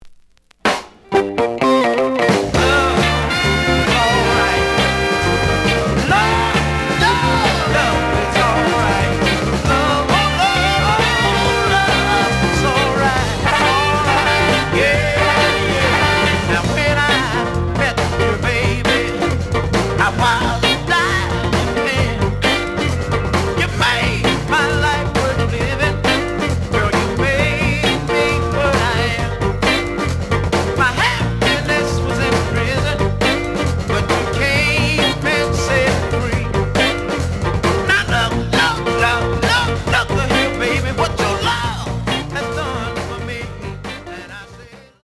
The audio sample is recorded from the actual item.
●Genre: Funk, 60's Funk
Slight noise on B side. A side plays good.)